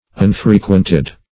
Unfrequented \Un`fre*quent"ed\, a. [Pref. un- + frequented.]